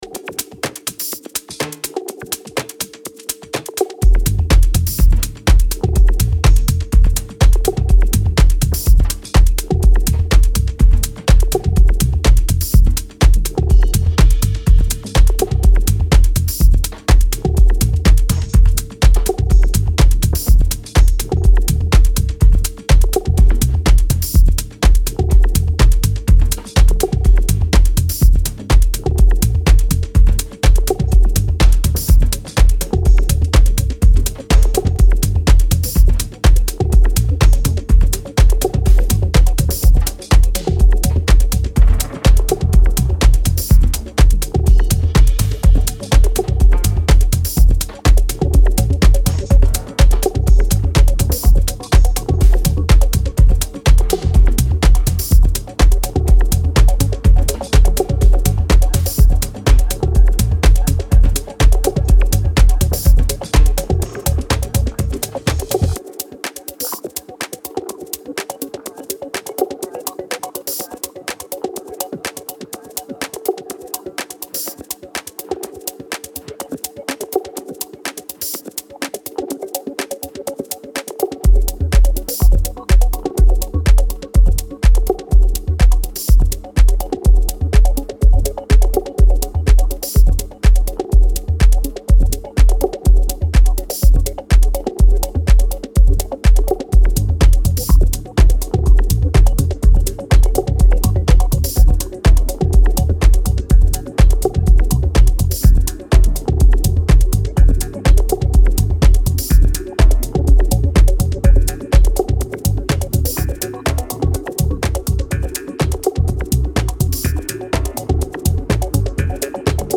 ここでは、繊細な音響処理が施されたダークでクールなミニマル・ハウスを全4曲展開。